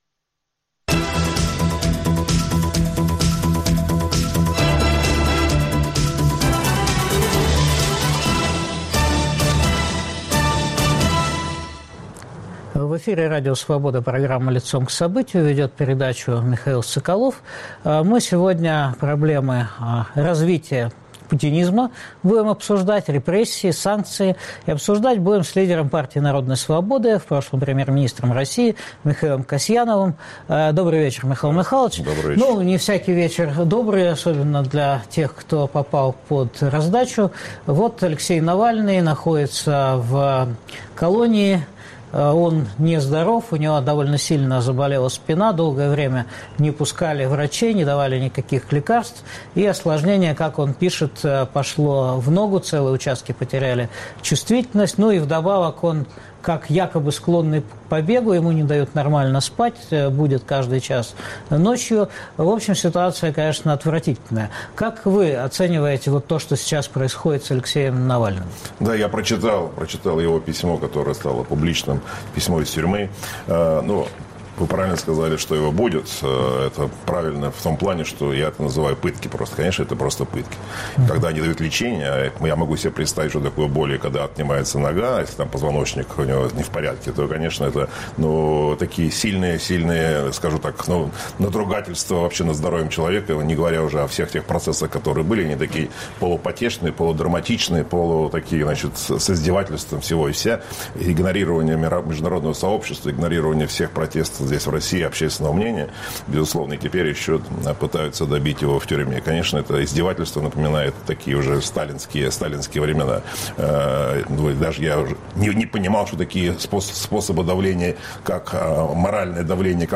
Как Кремль готовит Россию к выборам в Думу? Что происходит с экономикой? Обсуждаем с лидером Партии народной свободы Михаилом Касьяновым.